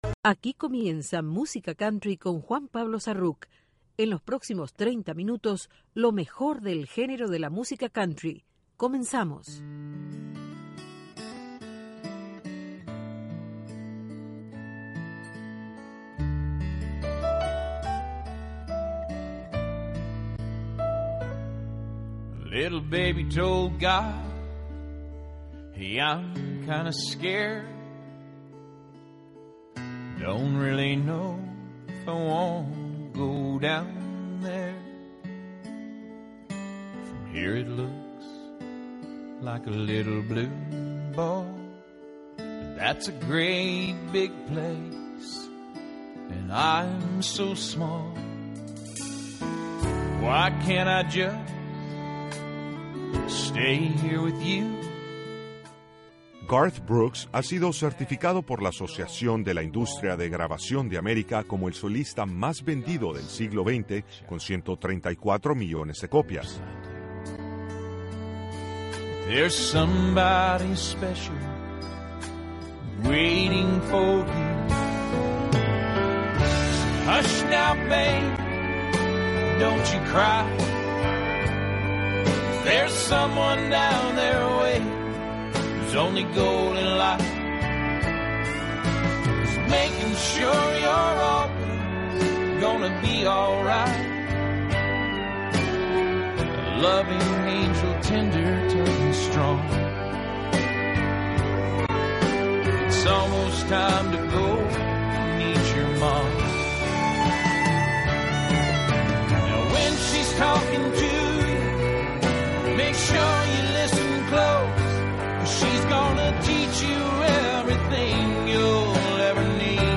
el programa musical